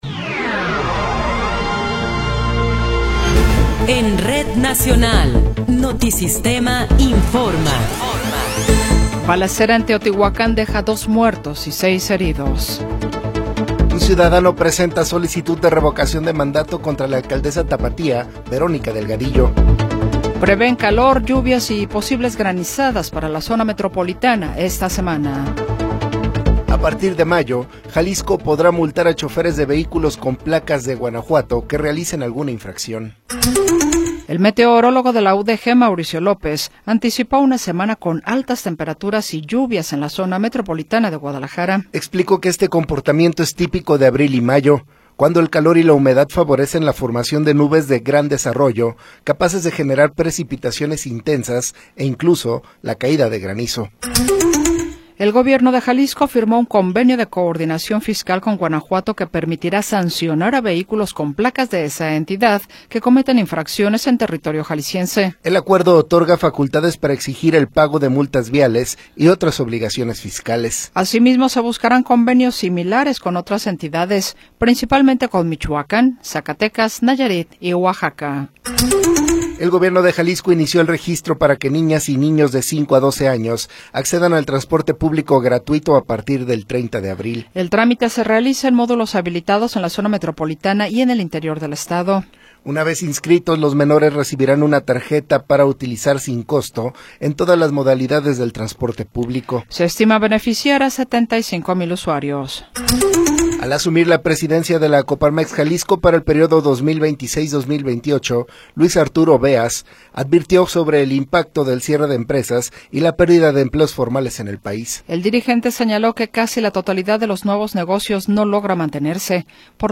Noticiero 20 hrs. – 20 de Abril de 2026